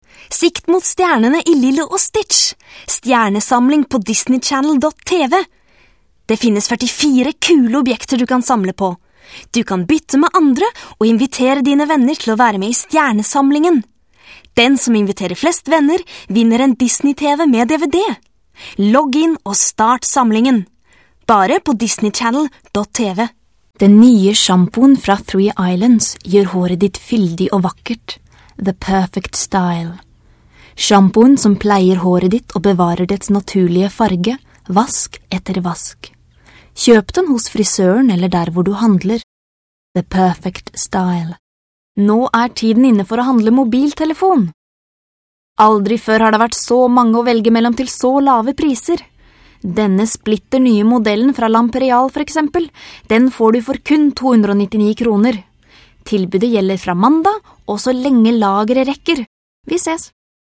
Norwegian. Actress, informative clarity.
Commercial Audio: